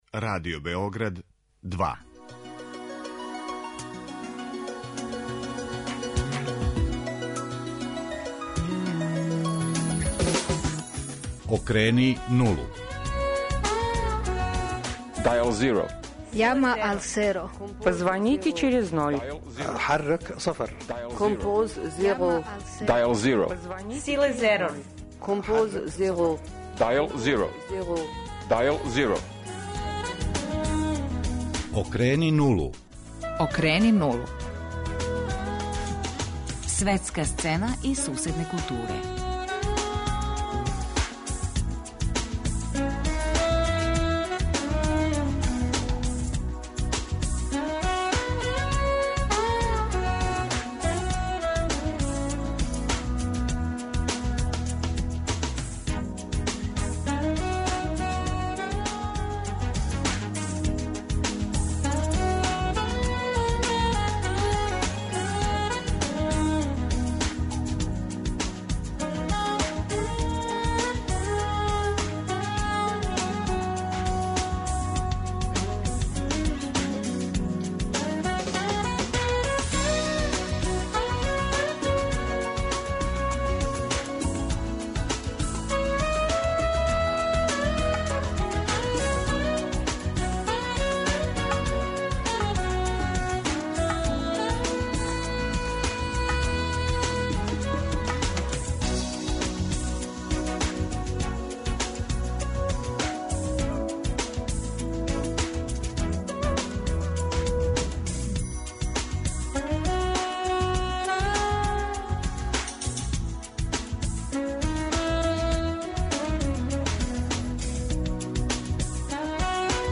Дописници Радио Београда 2 из Москве, Прага, Будимпеште, Загреба и Темишвара издвојиће и појаснити оно што привлачи пажњу јавности у Русији, Чешкој, Мађарској, Хрватској и Румунији.